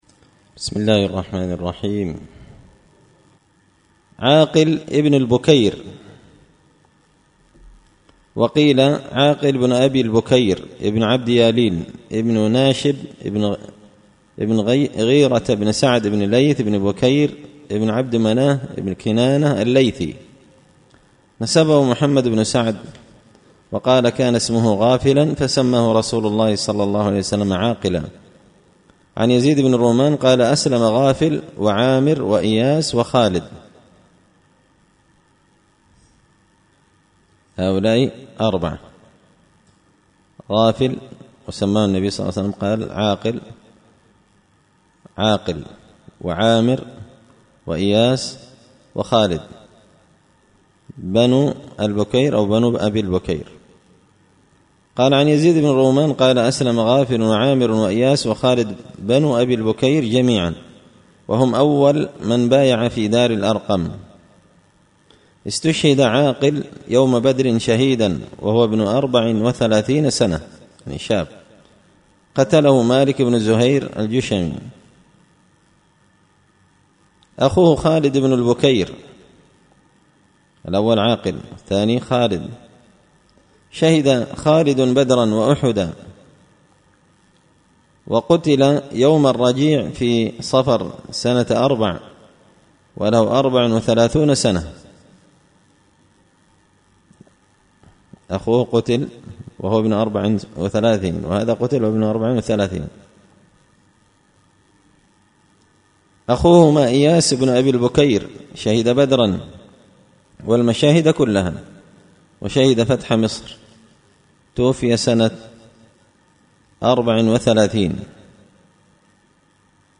الدرس 16إخوة أربعة عاقل عامر إياس خالد
دار الحديث بمسجد الفرقان ـ قشن ـ المهرة ـ اليمن